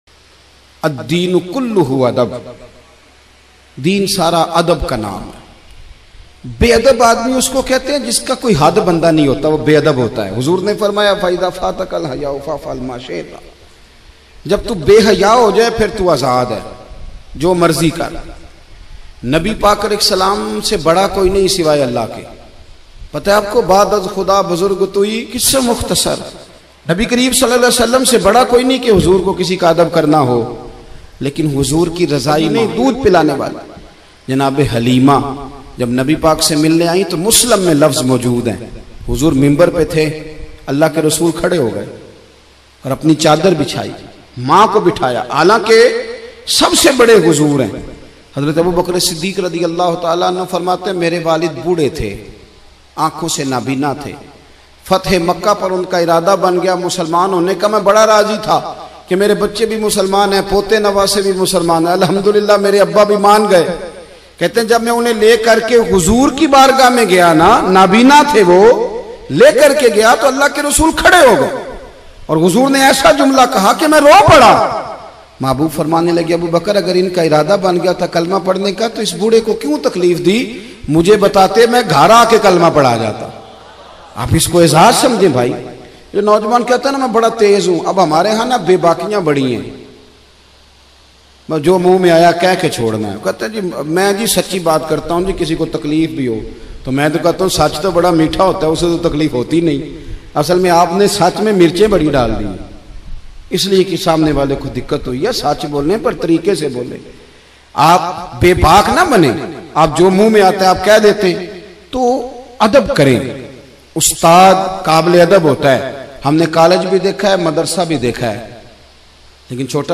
Islam Main Adab Ki Ahmiyat Bayan